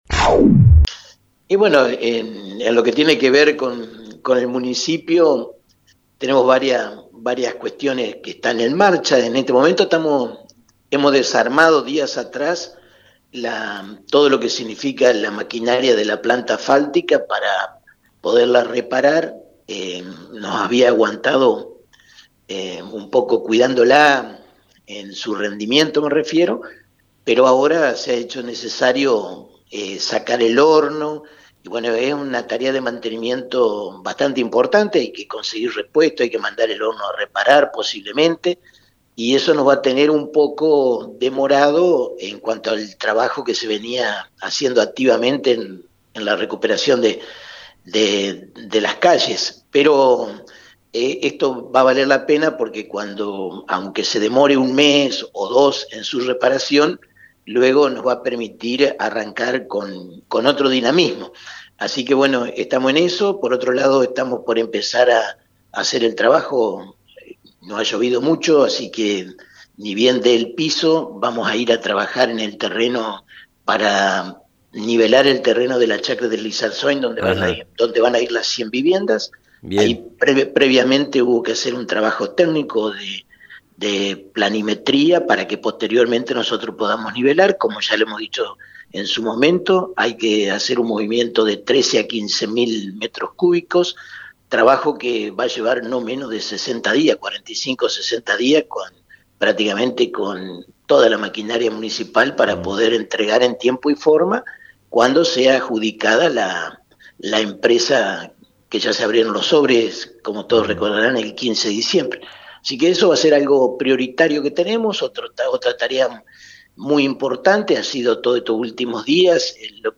por FM 90.3. el intendente Domingo Maiocco comentó cuáles fueron los últimos trabajos del 2021 y los que están en agenda para tratar en este comienzo de año.